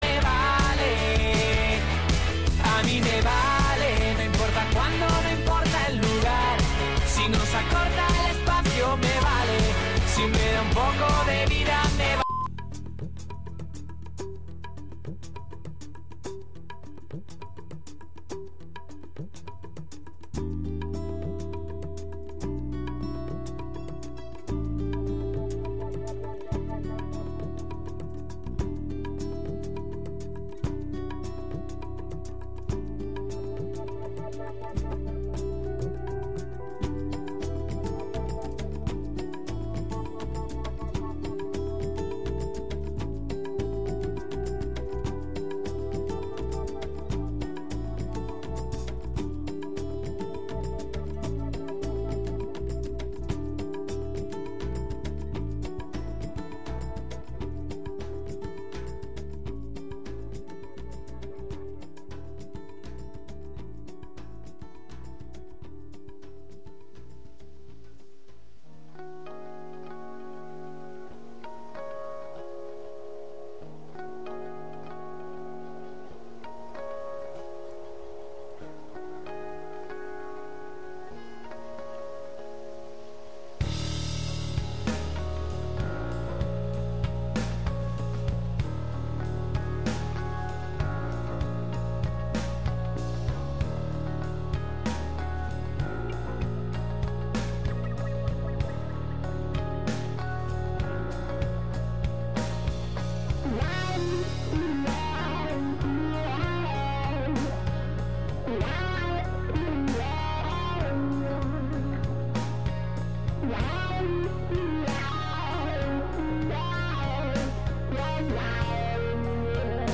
Recorreguts musicals pels racons del planeta, música amb arrels i de fusió. Sense prejudicis i amb eclecticisme.